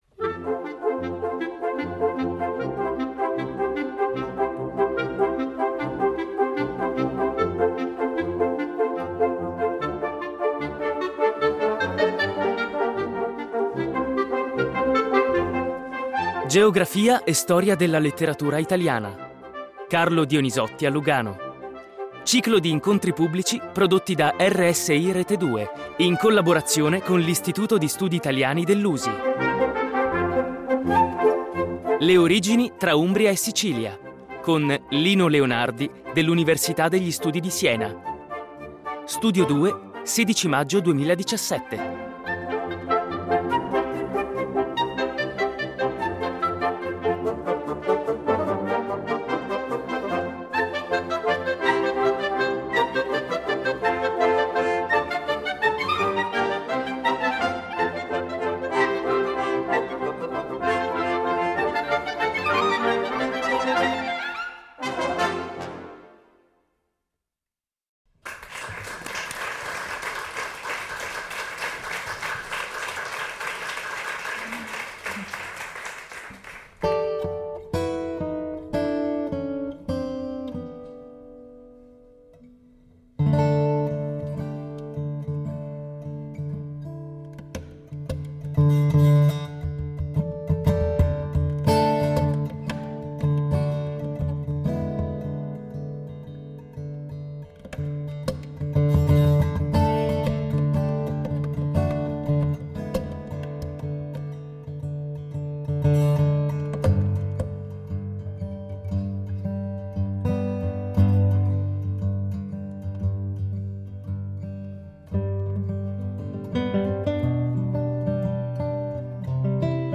Carlo Dionisotti a Lugano - Ciclo di incontri pubblici prodotti da RSI Rete Due